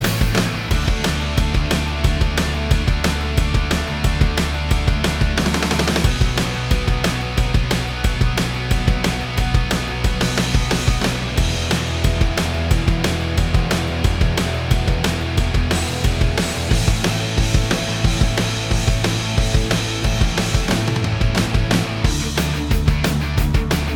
Minus Main Guitar Indie / Alternative 3:09 Buy £1.50